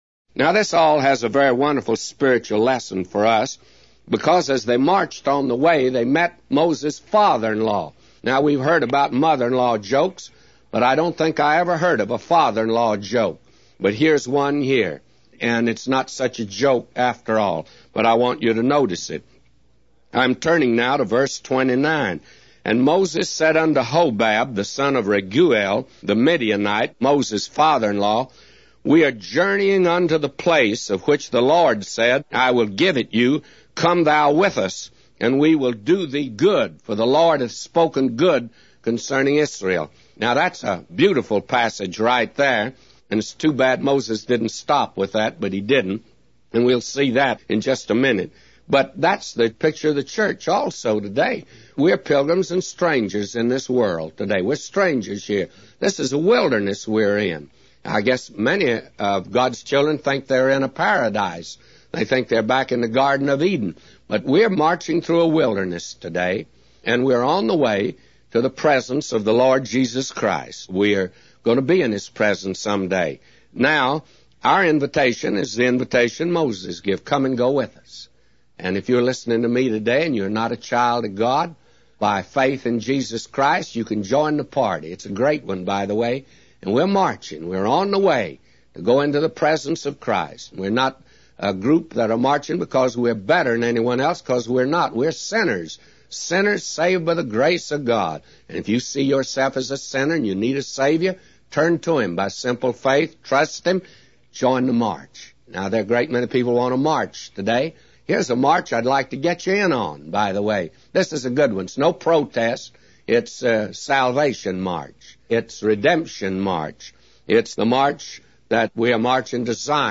A Commentary By J Vernon MCgee For Numbers 10:11-999